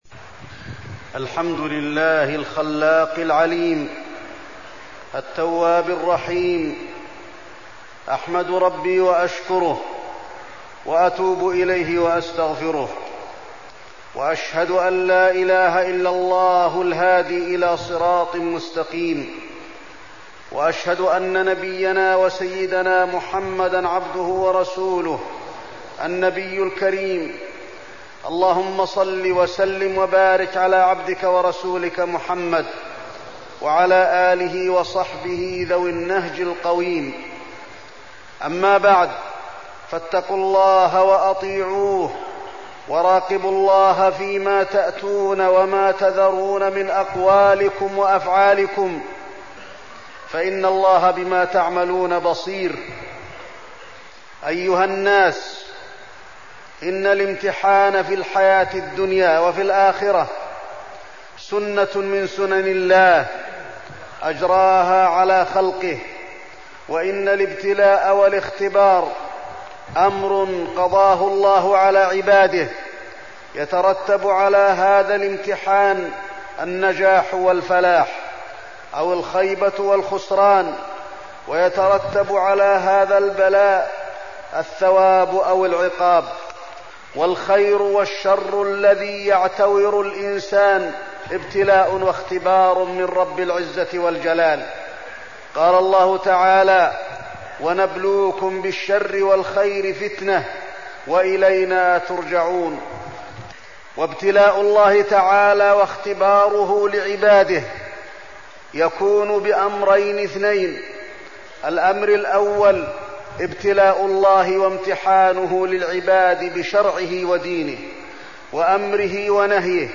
تاريخ النشر ٢٥ محرم ١٤١٦ هـ المكان: المسجد النبوي الشيخ: فضيلة الشيخ د. علي بن عبدالرحمن الحذيفي فضيلة الشيخ د. علي بن عبدالرحمن الحذيفي الابتلاء